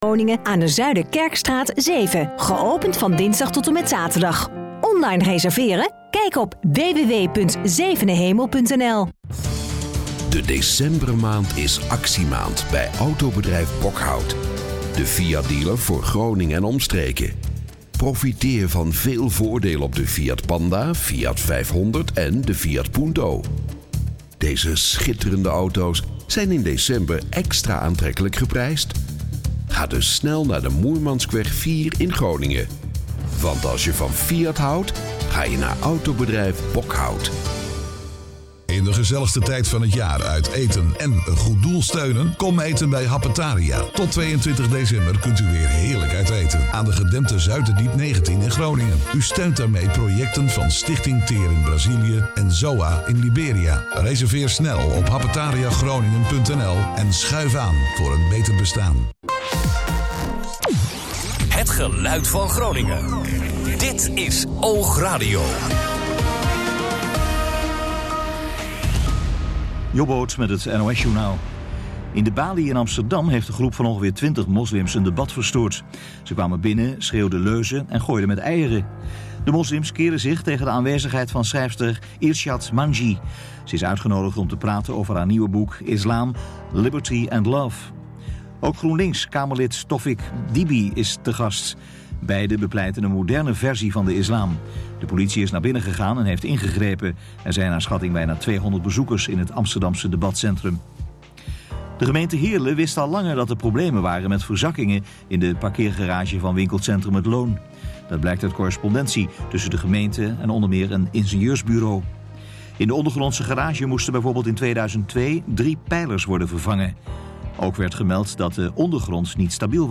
It’s something that in “normal” radio programming is often absent as is disrupts the gradual flow of fresh and stale pop tunes.